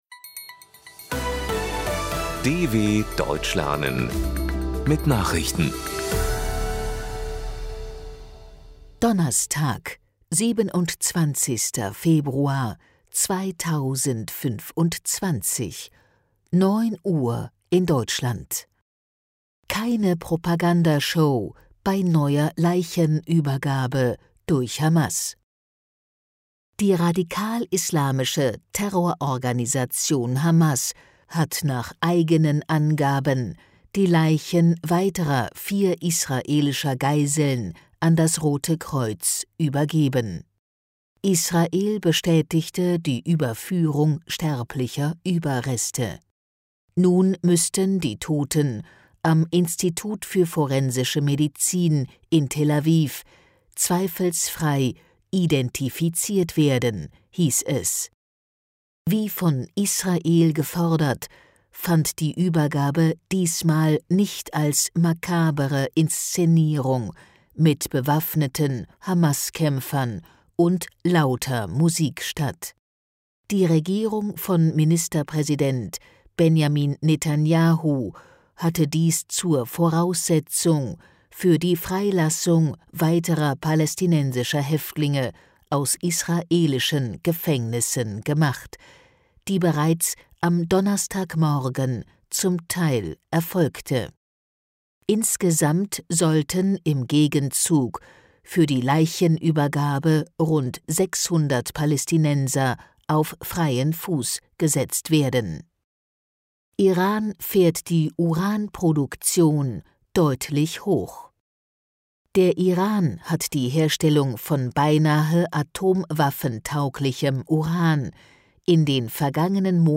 Das langsam und verständlich gesprochene Audio trainiert das Hörverstehen.